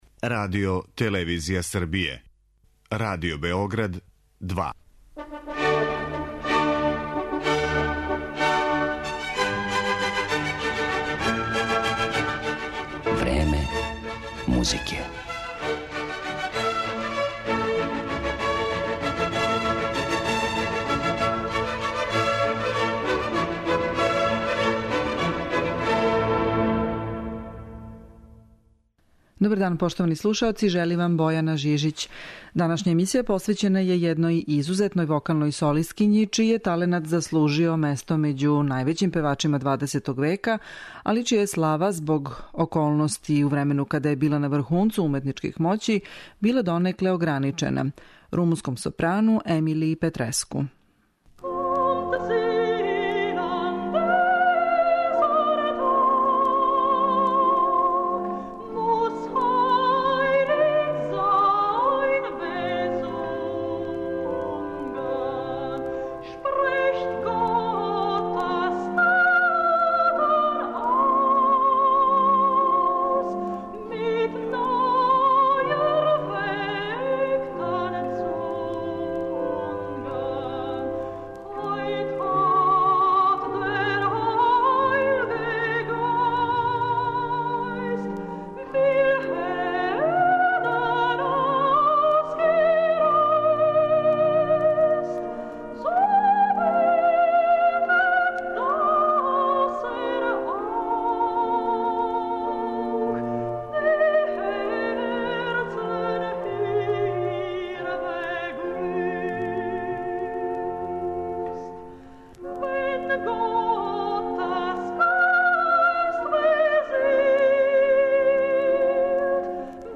Данашња емисија је посвећена једној изузетној вокалној солисткињи, чији је таленат заслужио место међу највећим певачима 20. века, али чија је слава била ограничена због политичких околности у времену када је била на врхунцу уметничких моћи - румунском сопрану Емилији Петреску.